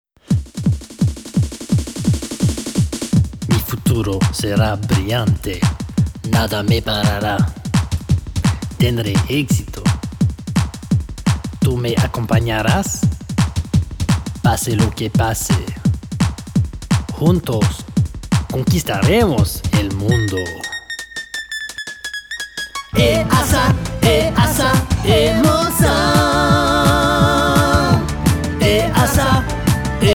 Spanish CD or Album Download